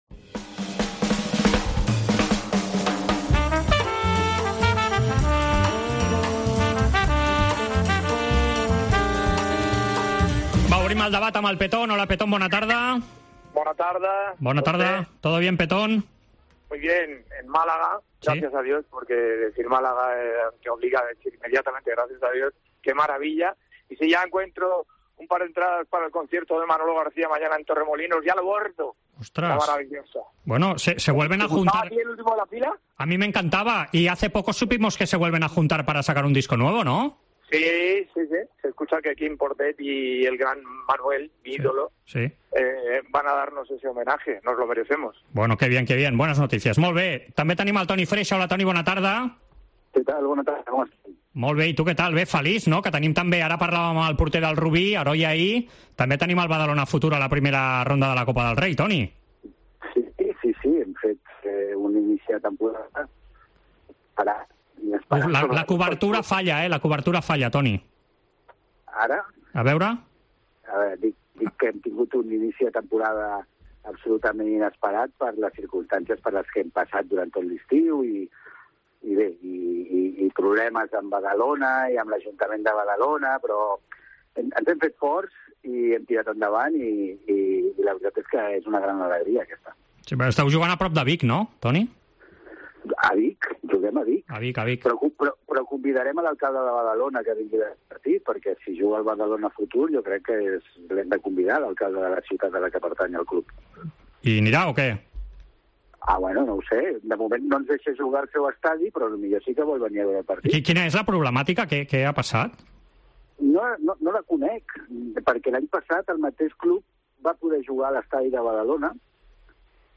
AUDIO: Els dos col·laboradors de la Cadena COPE repassen l'actualitat esportiva d'aquesta setmana.